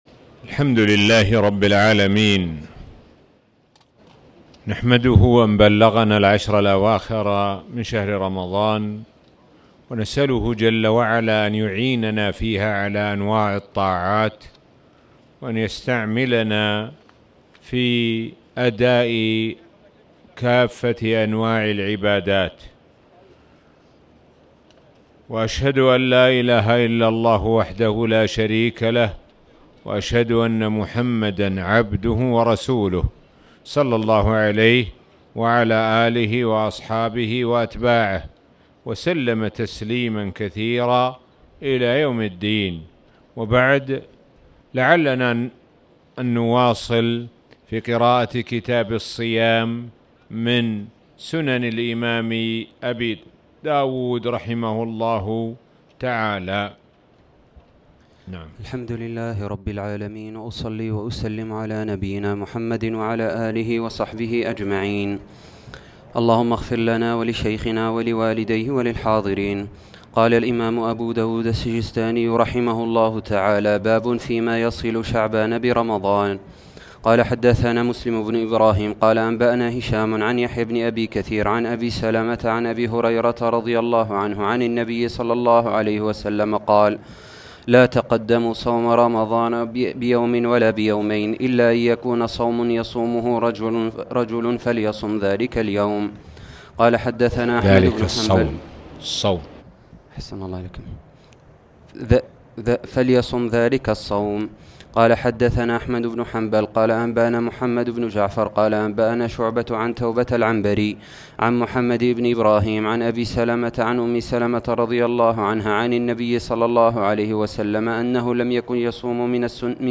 تاريخ النشر ٢١ شعبان ١٤٣٨ هـ المكان: المسجد الحرام الشيخ: معالي الشيخ د. سعد بن ناصر الشثري معالي الشيخ د. سعد بن ناصر الشثري باب فيما يصل شعبان برمضان